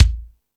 Lotsa Kicks(26).wav